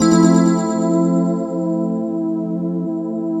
Index of /90_sSampleCDs/Best Service ProSamples vol.10 - House [AKAI] 1CD/Partition C/PADS